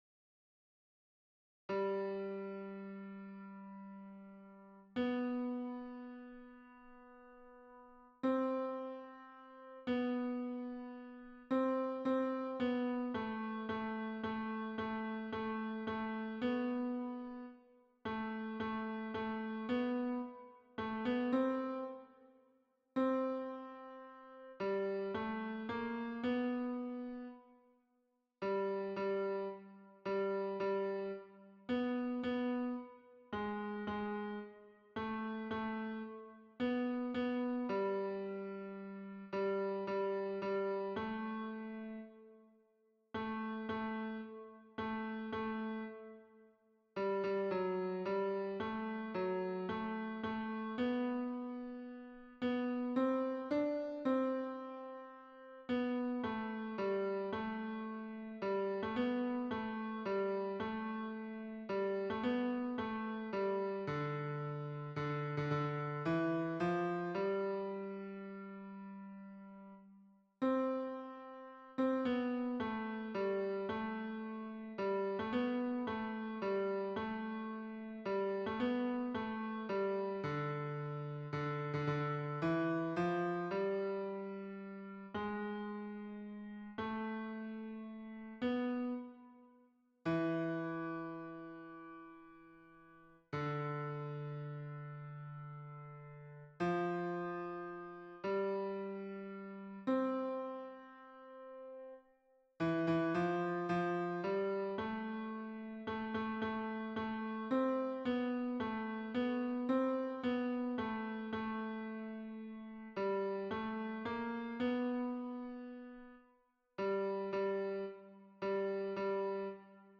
MP3 version piano
Ténor